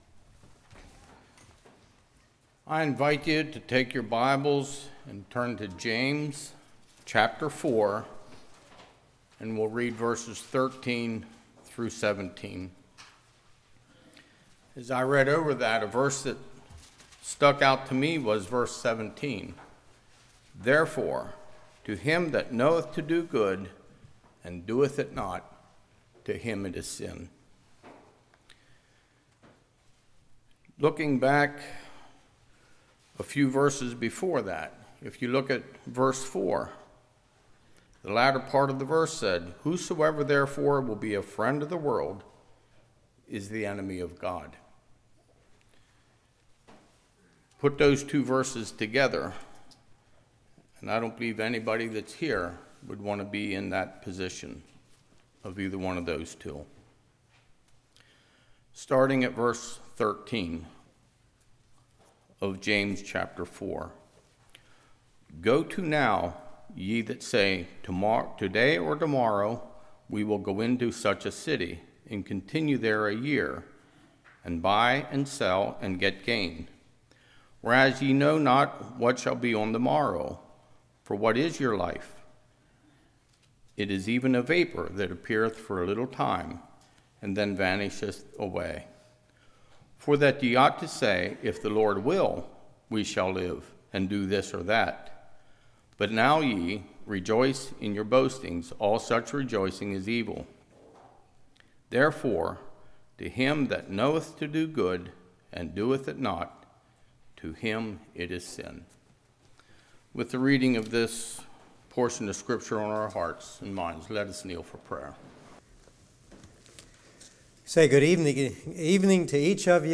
James 4:13-17 Service Type: Evening The Mist Presume of the Future Setting Goals is Proper What Does My Activities Demonstrate?